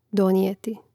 dònijēti donijeti